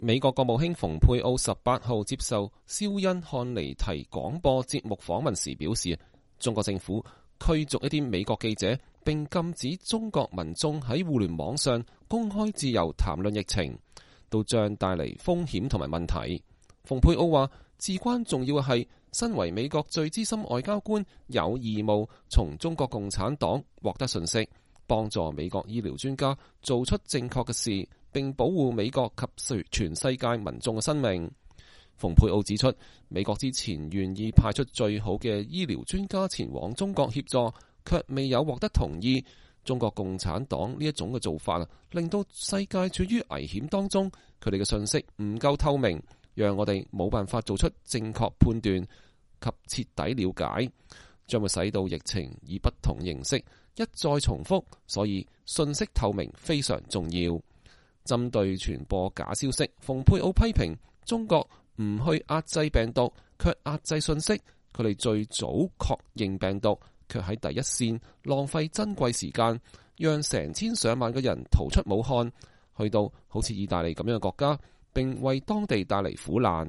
美國國務卿蓬佩奧18日接受肖恩・漢尼提(Sean Hannity)廣播節目訪問時表示，中國政府驅逐一些美國記者，並禁止中國民眾在互聯網上公開自由談論疫情，這將帶來風險和問題。